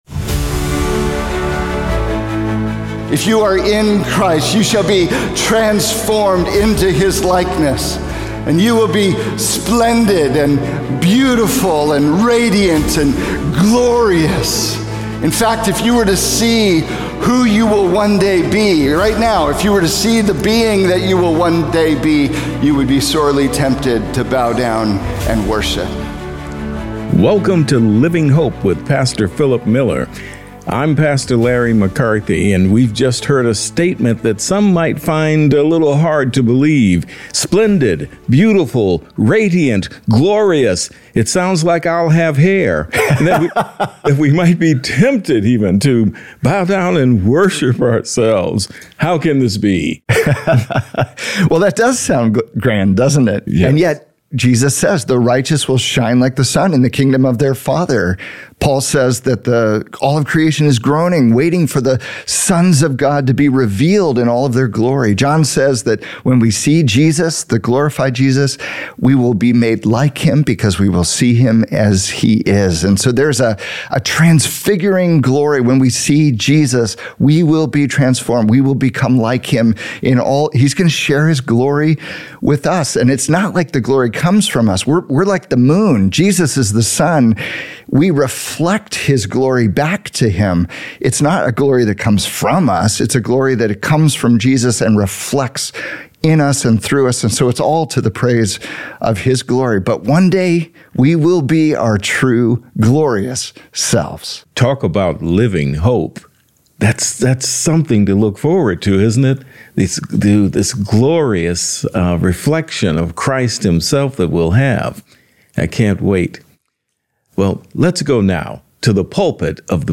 Praying Like “Royalty Incognito” | Radio Programs | Living Hope | Moody Church Media